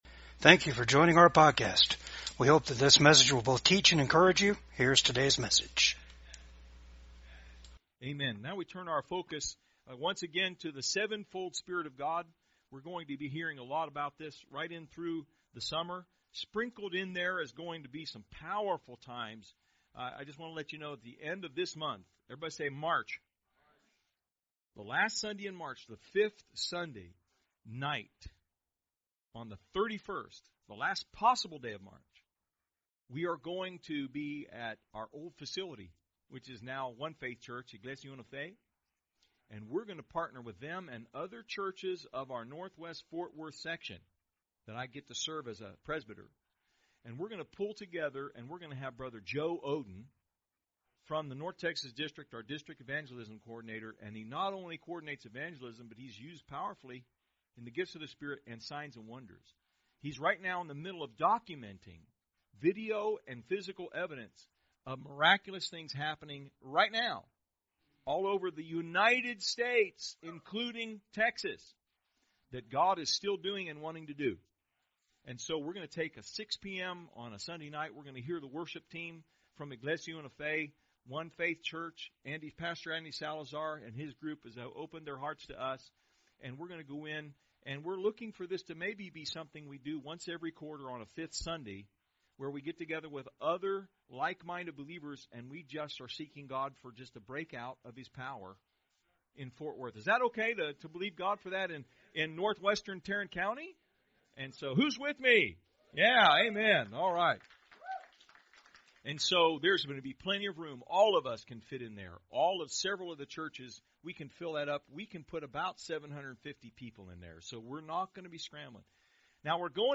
Service Type: VCAG SUNDAY SERVICE